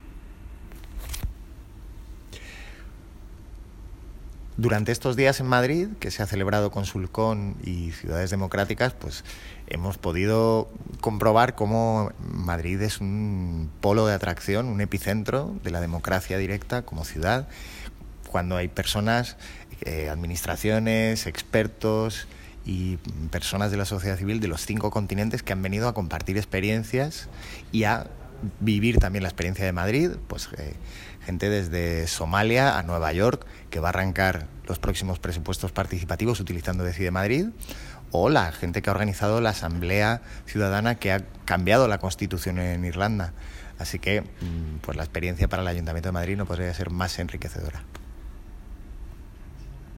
Palabras del delegado de Participación y Transparencia, Pablo Soto, durante la clausura de las conferencias, en MedialabPrado:
Pablo-Soto-Clausura-ConsulCon.m4a